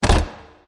Added sound notification for login failure and custom font for the login ...